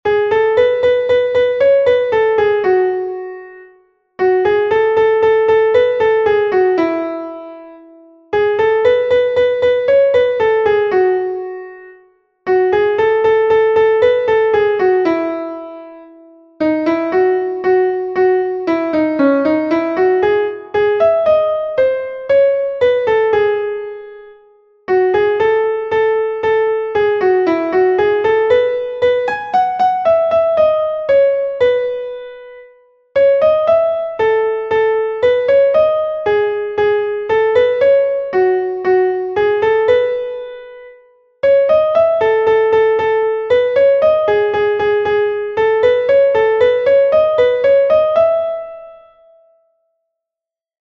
最近、夢の中にあるメロディが現れてきました。
こんなメロディです。